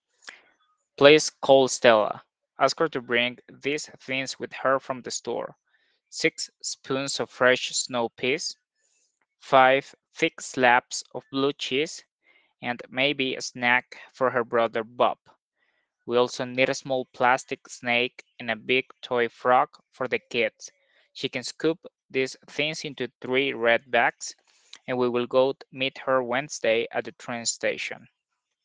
A Venezuelan Accent
El orador es nativo de Punta de Piedra, Estado Barinas, Venezuela, y se observa un moderado acento en su pronunciación.
Venezuelan-accent..mp3